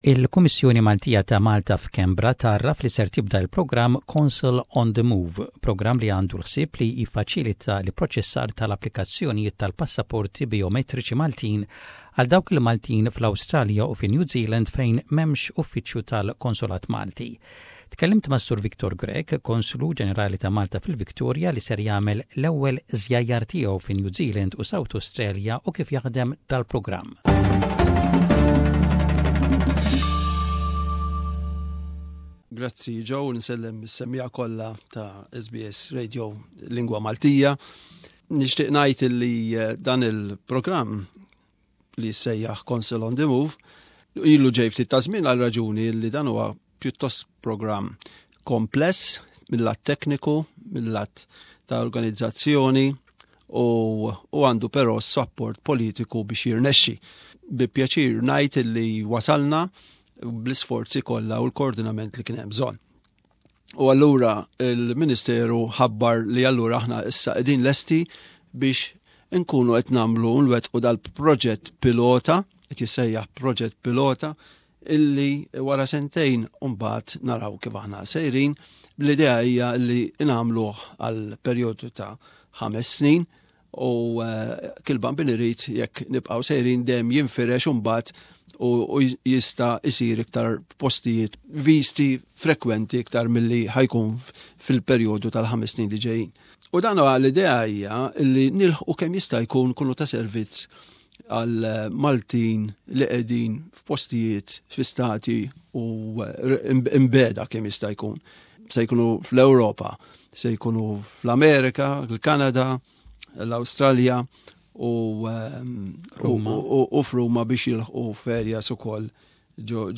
jitkellem mas-Sur Victor Grech, Konslu Ġenerali ta Malta